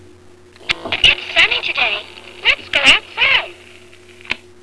"Best Friend Cynthia" was a talking doll. She worked by inserting a miniature record into a slot on her side and pressing a button on her back.